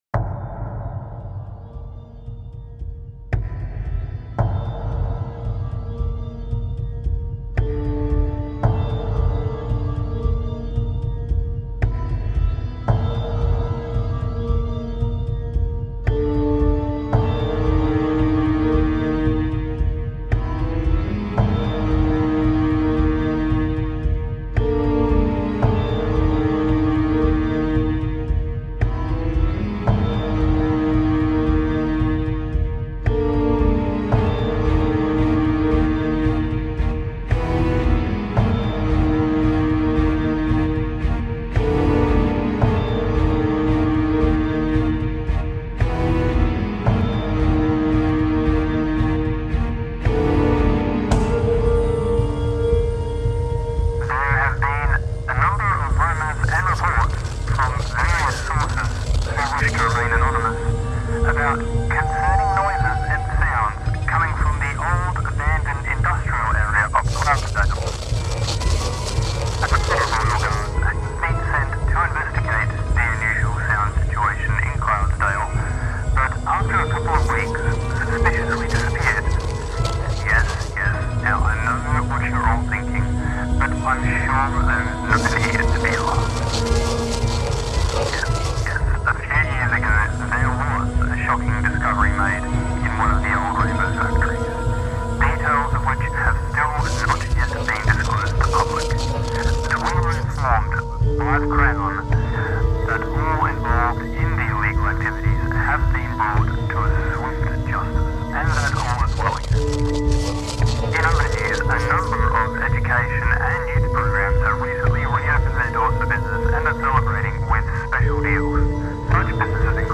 2 parter song